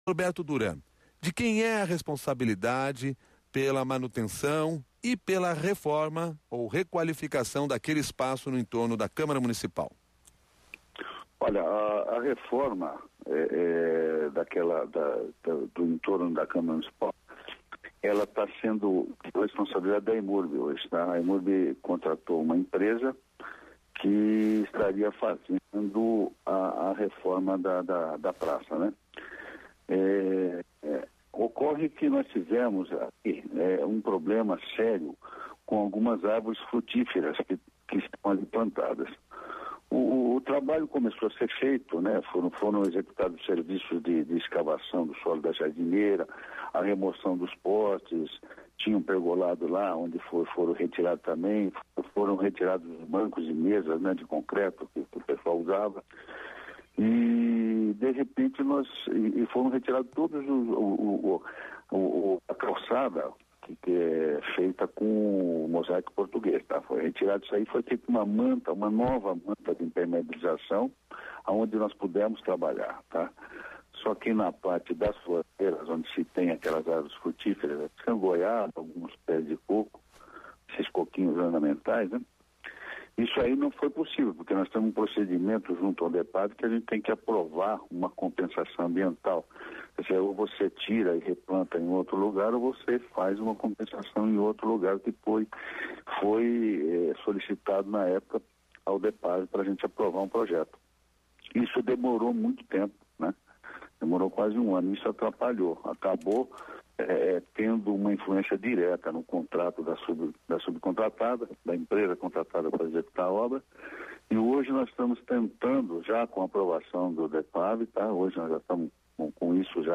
Árvores frutíferas que exigiram cuidados especiais foram a justificativa da Emurb para o atraso nas obras de “requalificação paisagística no entorno da Câmara Municipal”.